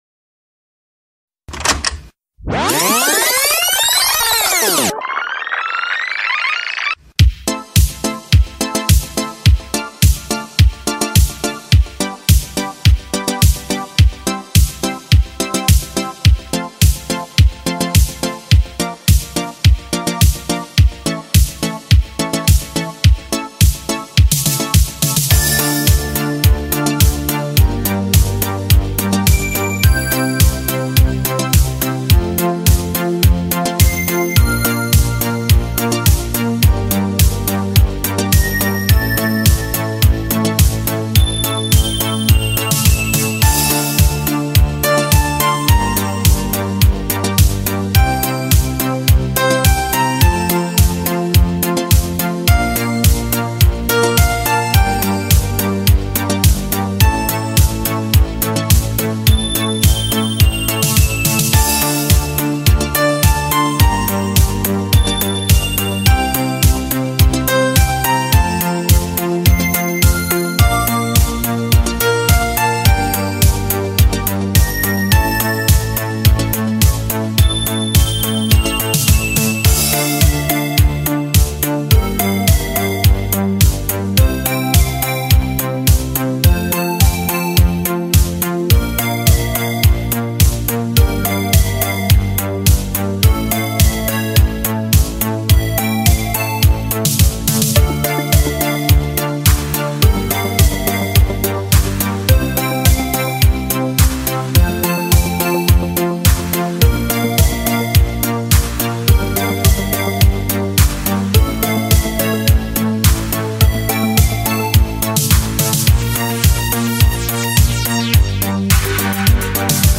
Пару инструментальчиков в этом стиле)